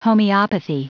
Prononciation du mot homeopathy en anglais (fichier audio)
homeopathy.wav